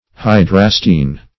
hydrastine - definition of hydrastine - synonyms, pronunciation, spelling from Free Dictionary
Search Result for " hydrastine" : The Collaborative International Dictionary of English v.0.48: Hydrastine \Hy*dras"tine\, n. (Chem.)
hydrastine.mp3